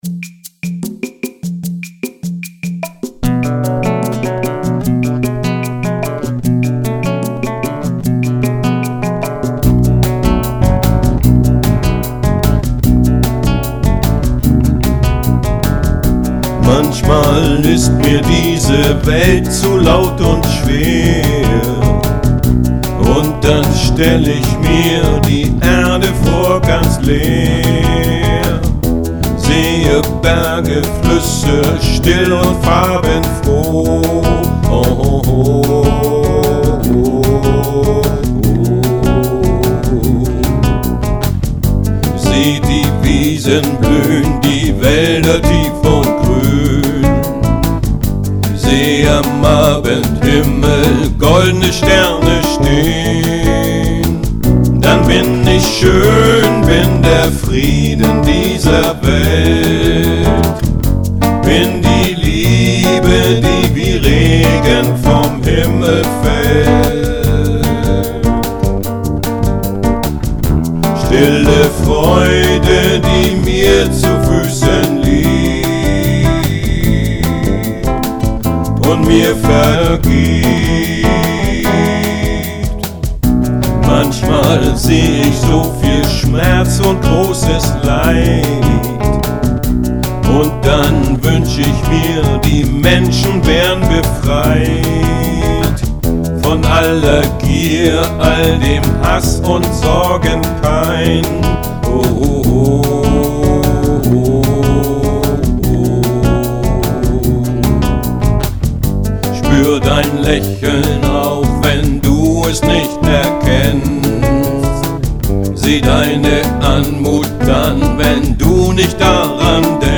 Die Audio-Tracks sind mit allen Instrumenten (und Vocals) von mir allein eingespielt worden (home-recording).
Seit Frühjahr 2012 benutze eine etwas bessere Aufnahmetechnik (mit einem externen Audio-Interface und einem besseren Mikrofon).